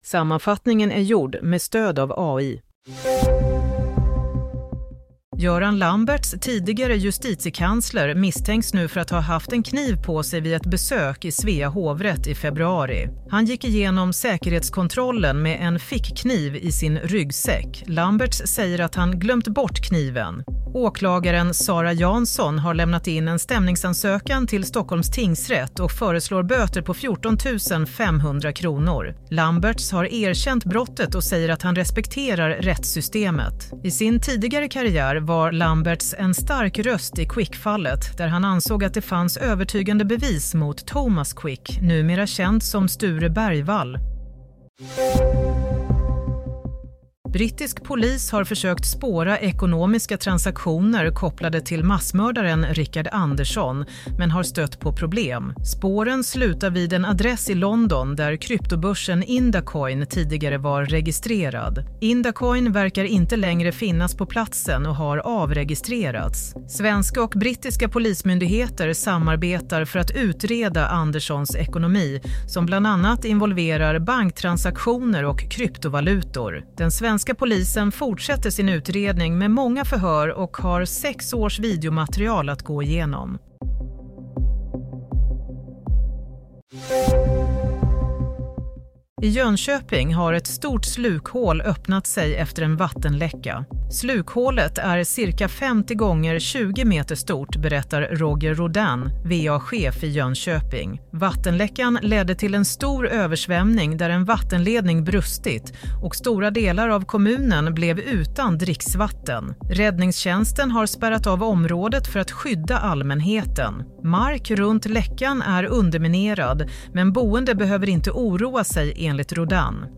Nyhetssammanfattning – 24 mars 16:00